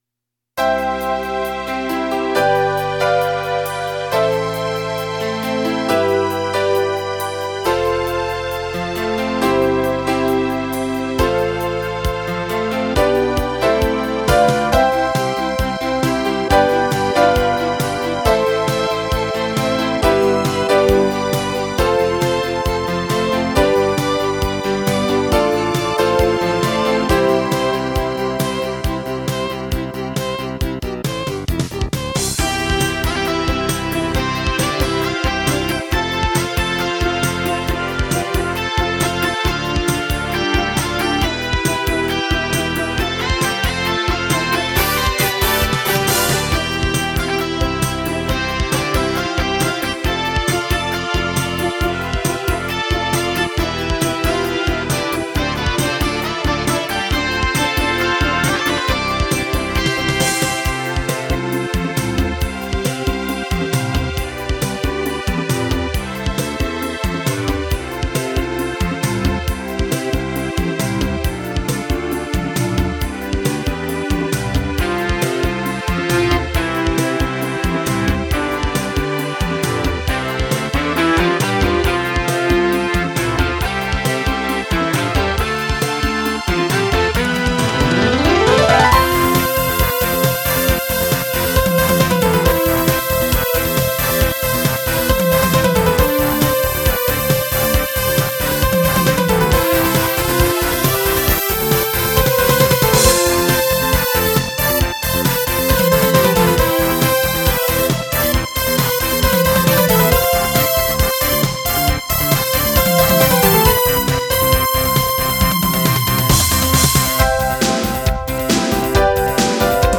始めてＮＲＰＮやエクスクルーシブを使いましたが、いかんせん、楽器の構造が分かってないので、上手く音色を作れませんでした。